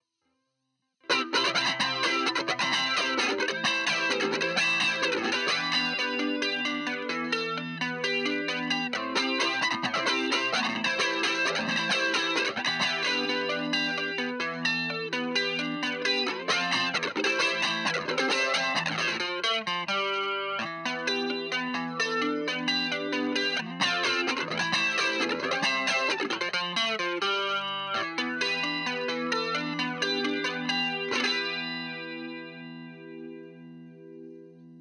Звук консервной банки, жесткий и сухой.
Сильная атака и звук консервной банки, без тела и сустейна, очень сухой.
В первом примере играю на ясеневом японском телекастере Bill Lawrence начала 90-х с родными звучками через амплитуб 4 + импульс Red Wire и там прекрасно это слышно.